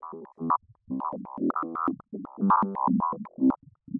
Lab Work (Layer Synth) 120BPM.wav